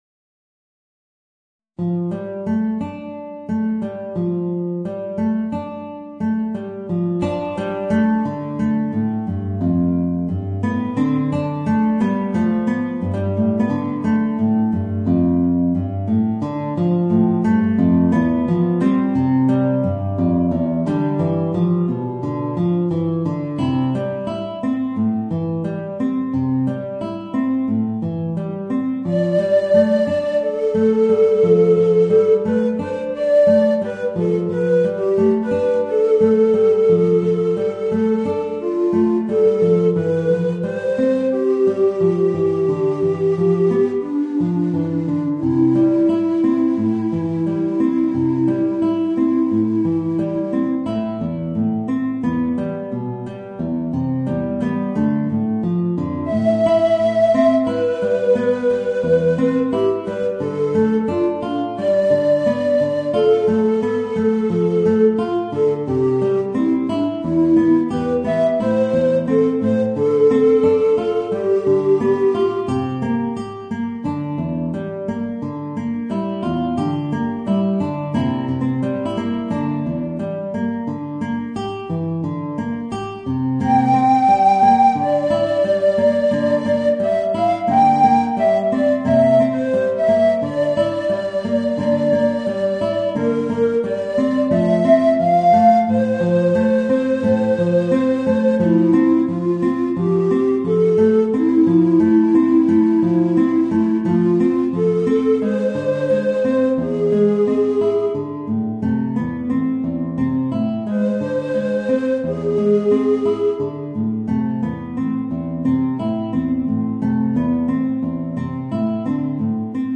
Voicing: Guitar and Soprano Recorder